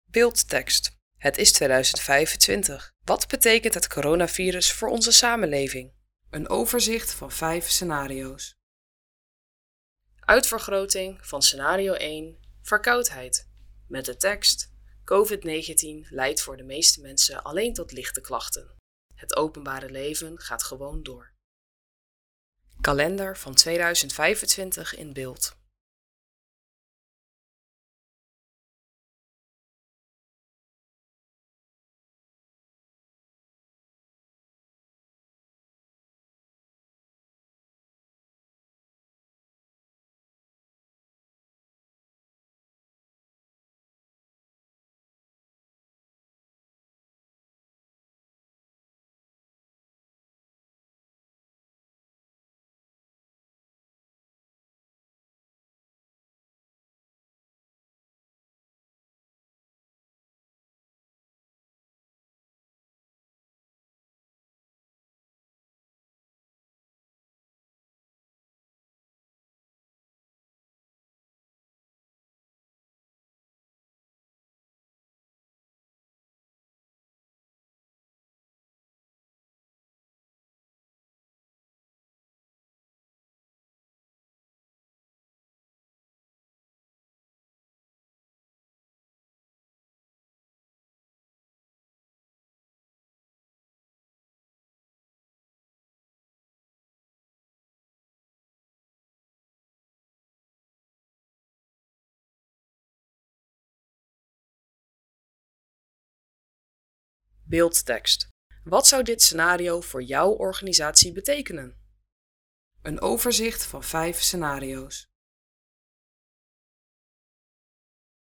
Rustige muziek speelt
Lo-fi muziek speelt